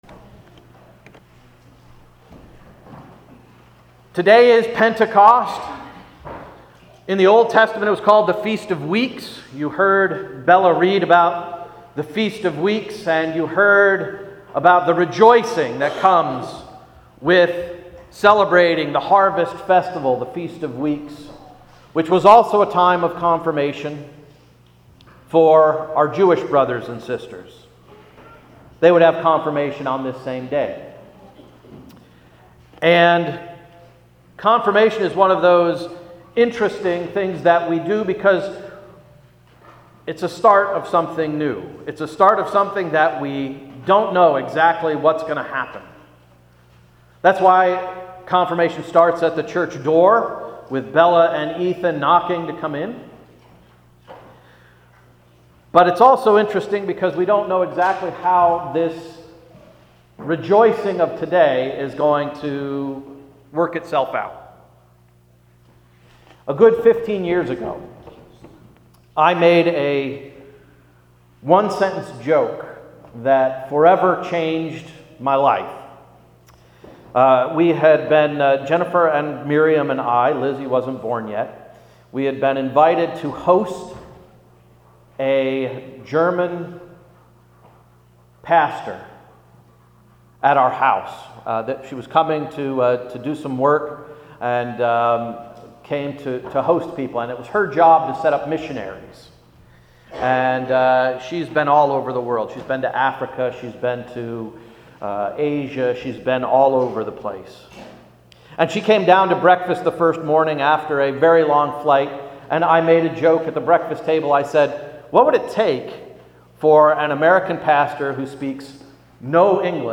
May 15, 2016 Sermon– “Feast of Weeks”